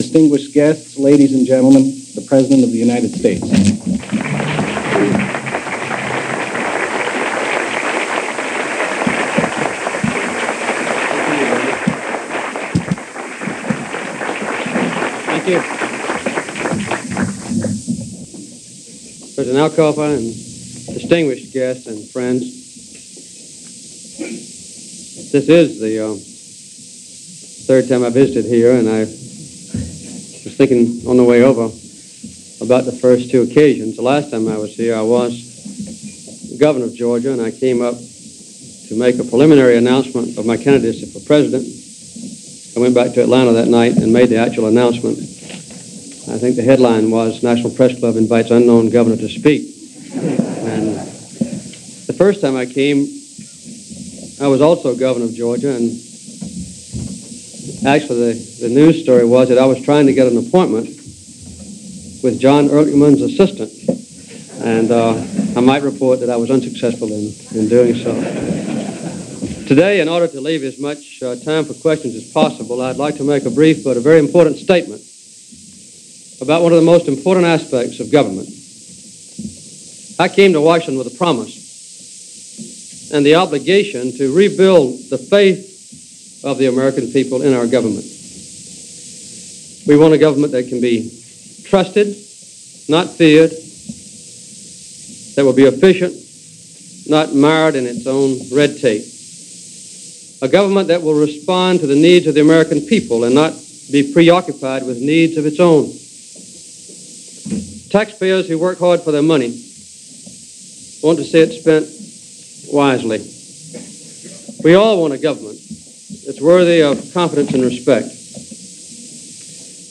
Jimmy Carter - National Press Club - March 2, 1978 - White House Press Corps Q&A at The National Press Club in Washington.
The Civil Service question became something of a centerpiece for this press conference, held at the National Press Club on March 2, 1978.
Jimmy-Carter-National-Pres-Club-March-2-1978.mp3